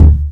Kick (Timbo).wav